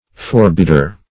Forbidder \For*bid"der\, n. One who forbids.